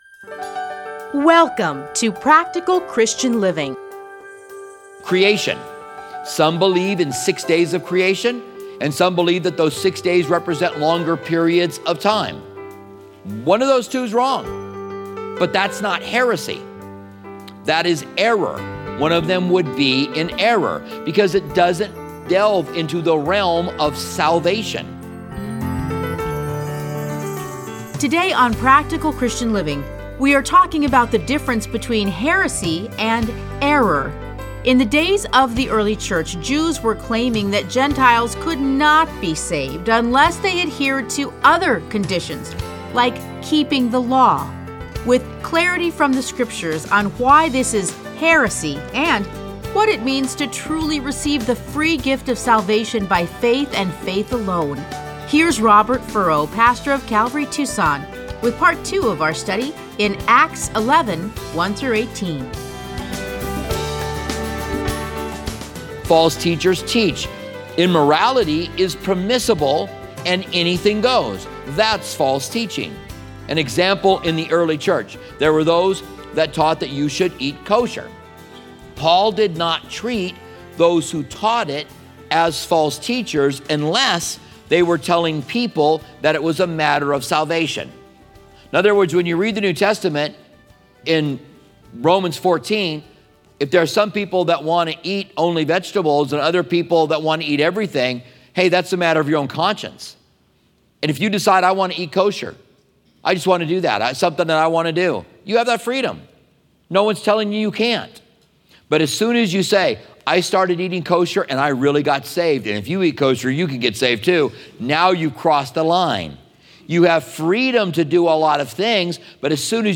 Listen to a teaching from Acts 11:1-18.